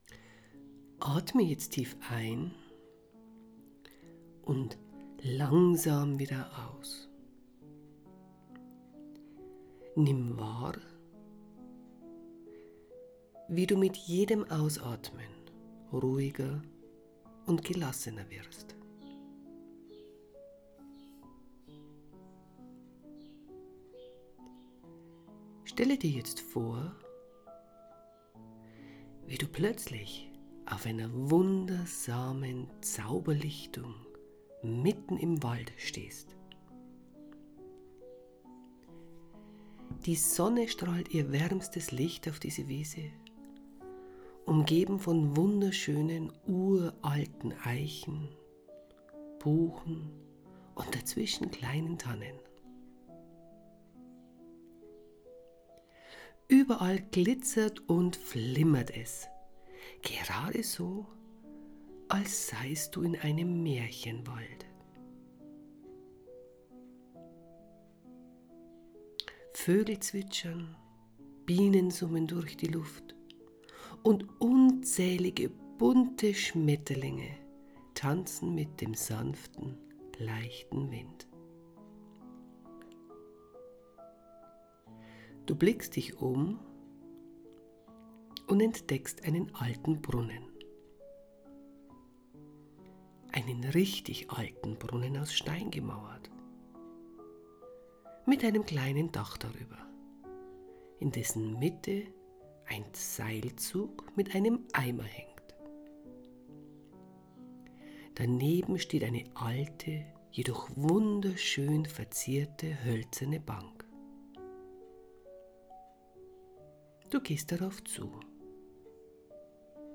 4 Minuten Meditation zu innerer Klarheit, Kraft und Frische!